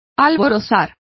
Complete with pronunciation of the translation of exhilarating.